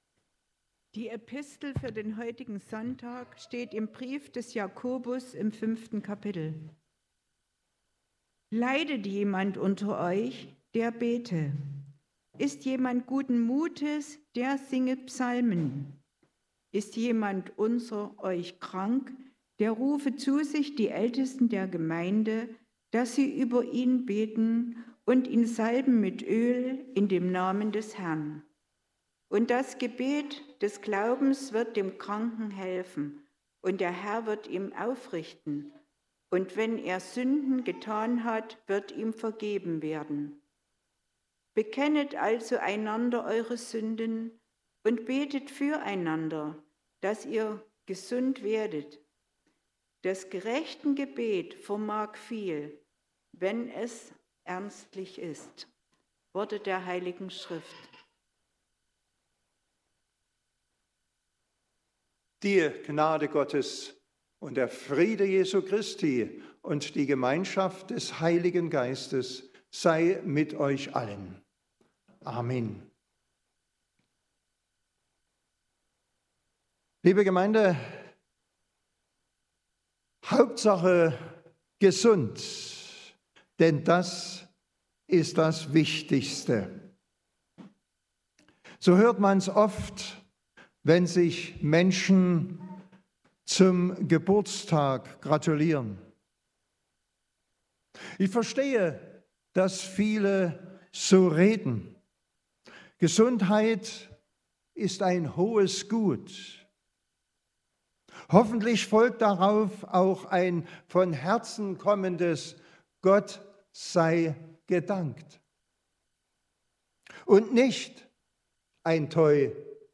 13-16 Gottesdienstart: Predigtgottesdienst Obercrinitz Gesundheit ist ein hohes Gut.